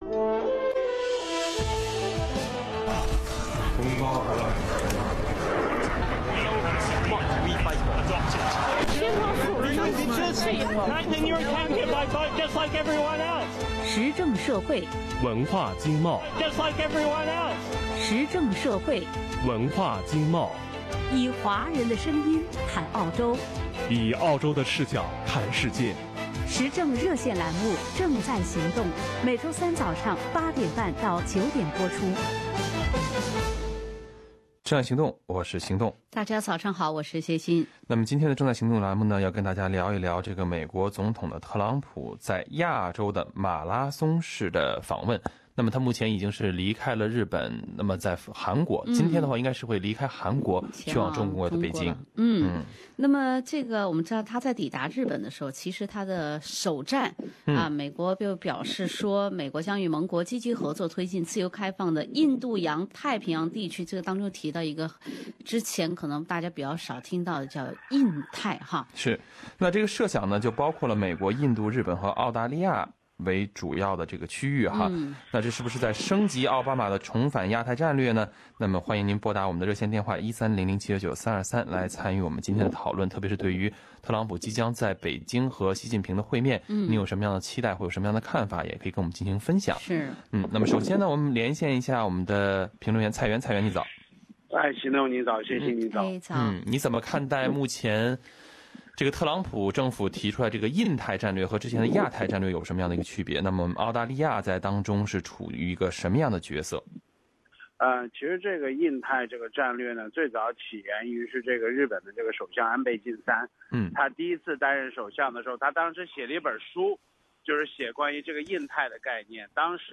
听众热议特朗普总统亚洲之行。